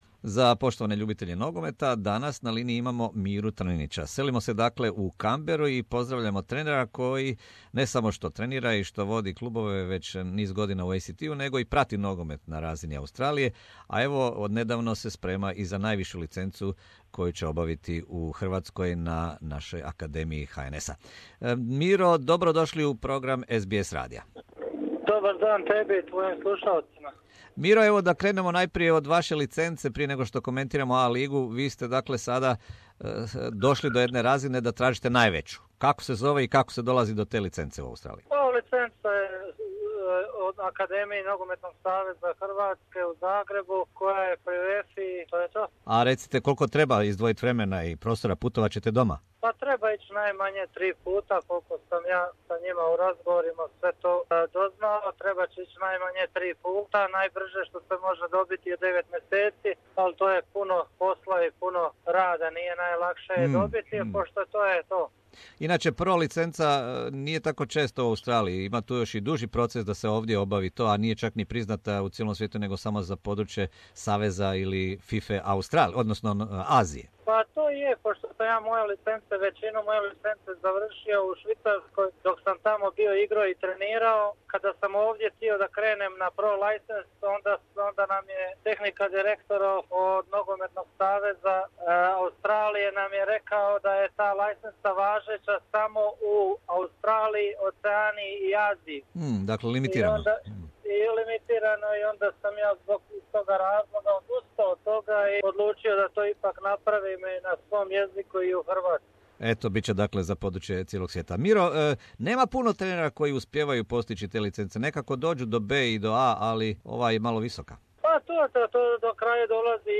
Ovaj nogometni stručnjak u razgovoru za program Radija SBS na hrvatskkom jeziku komentira i aktualna pitanja klubova i igrača A lige Australije.